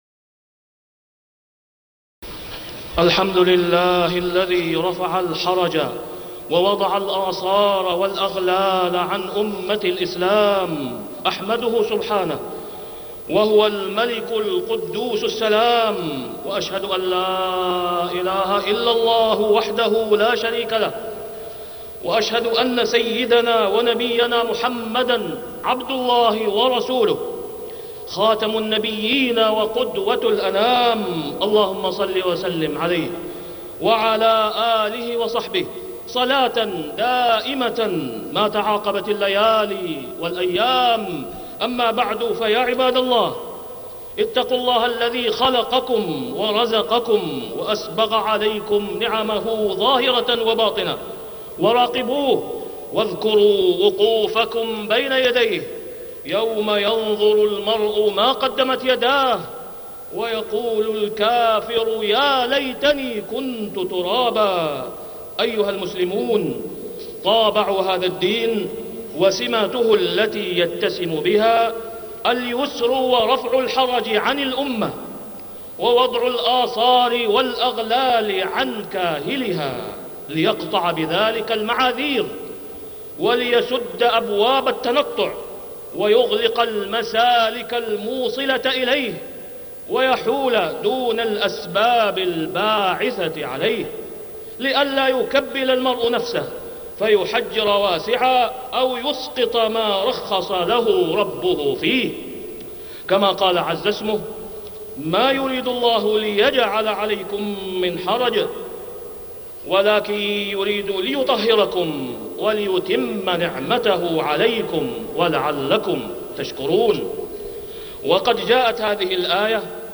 تاريخ النشر ٢٧ ربيع الأول ١٤٢٦ هـ المكان: المسجد الحرام الشيخ: فضيلة الشيخ د. أسامة بن عبدالله خياط فضيلة الشيخ د. أسامة بن عبدالله خياط وما جعل عليكم في الدين من حرج